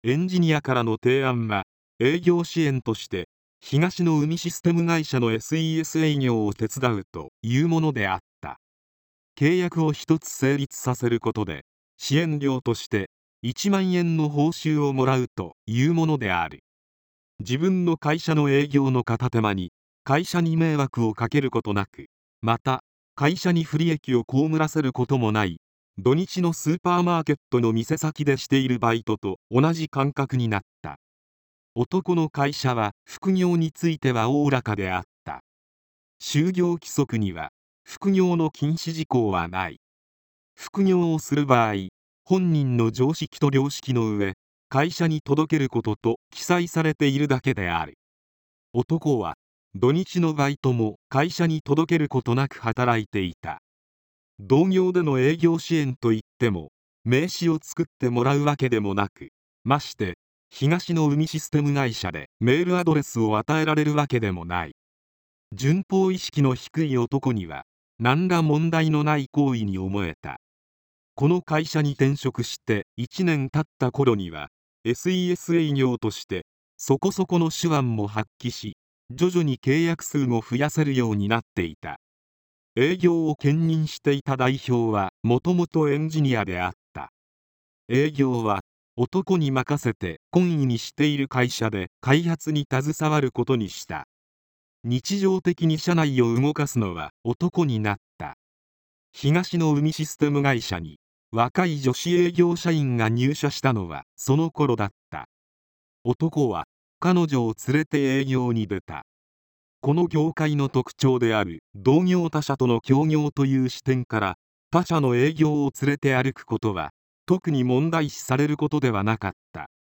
今回は、そんな音声合成の実験として、ふたつばかりのテキストを読み上げさせてみました。
悪くはないのですが、しょせんは音声合成です。
とはいえ、読み上げの速さ、間の取り方は今回の音声合成の感じで悪くないと思います。